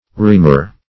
R'eaumur \R['e]`au`mur"\ (r[asl]`[=o]`m[.u]r"), a.